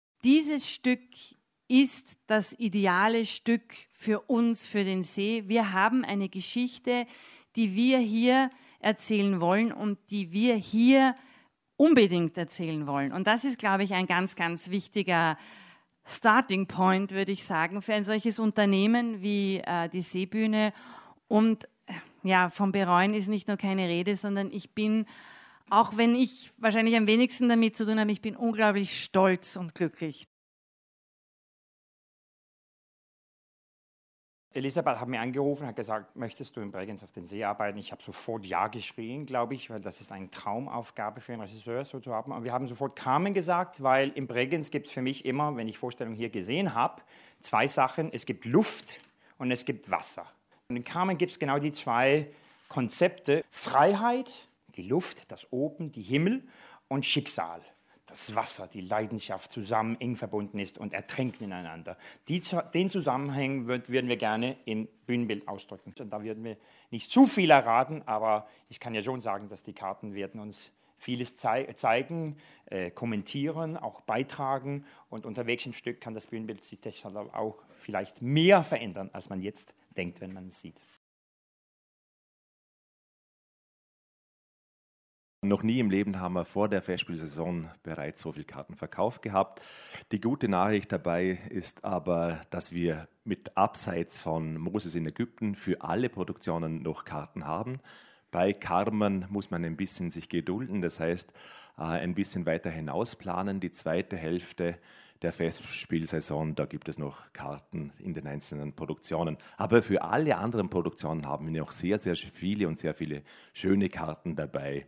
Pressetag 2017 Radio-Feature 2